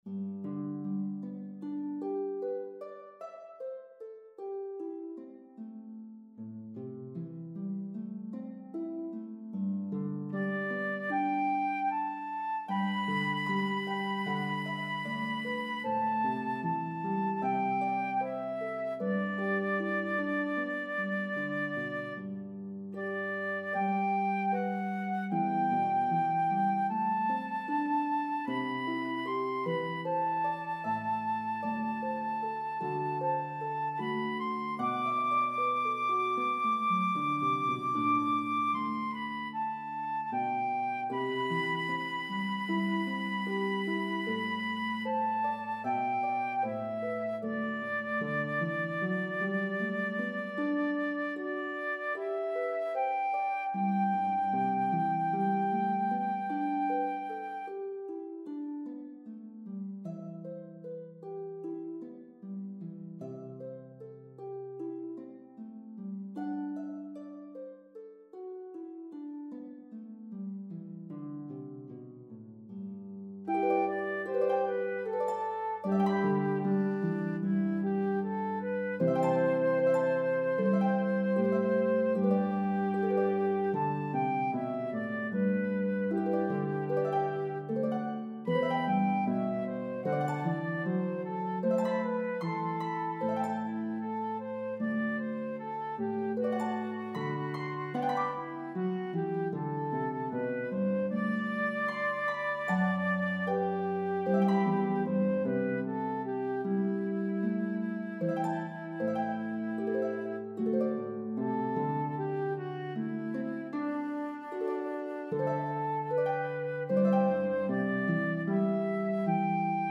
Harp and Flute version